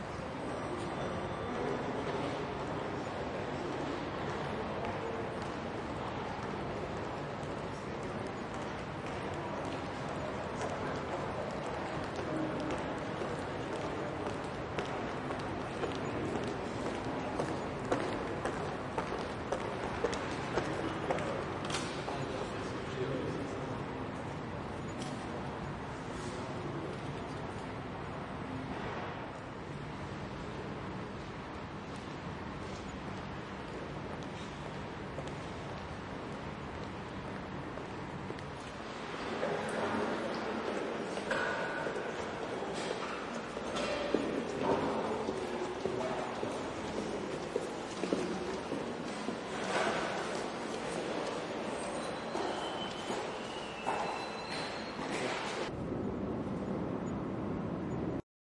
几乎空无一人的巴黎地铁站
描述：用ZOOM H4在巴黎Montparnasse地铁站的夜晚录制的样本。
Tag: 氛围 现场 地铁 巴黎 录音 立体声